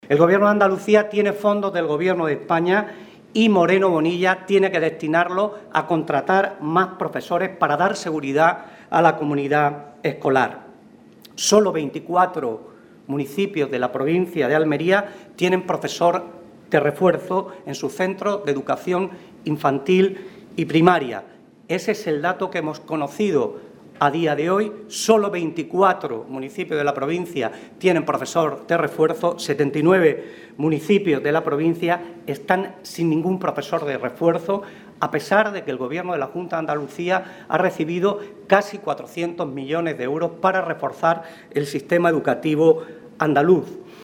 En concreto, los colegios de 79 municipios de la provincia no van a contar con ningún profesor de refuerzo de los anunciados por la Junta de Andalucía, según ha revelado en rueda de prensa el secretario general del PSOE de Almería, José Luis Sánchez Teruel.